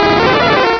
sovereignx/sound/direct_sound_samples/cries/fearow.aif at master